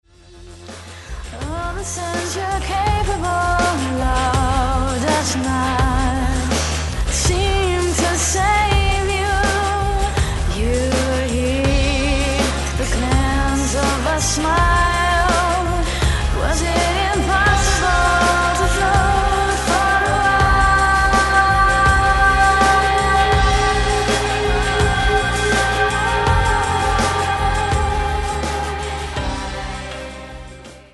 AQUÍ SÓLO UNA PEQUEÑA PARTE de la versión del disco